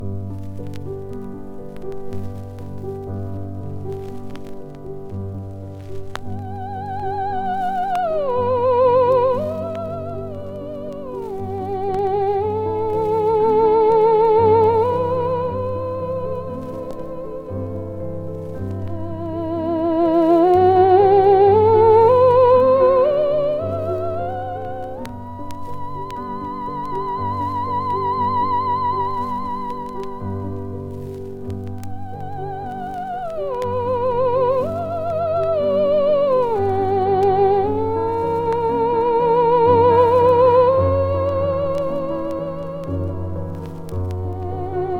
超絶な演奏に驚きます。